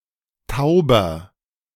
The Tauber (German: [ˈtaʊbɐ]
De-Tauber.ogg.mp3